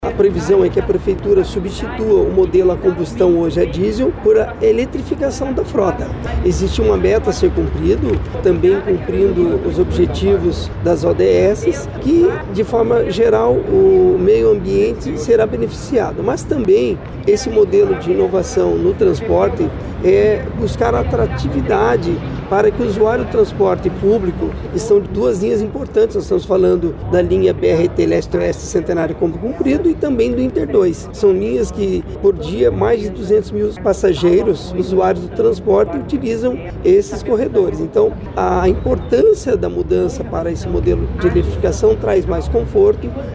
O que diz o líder do prefeito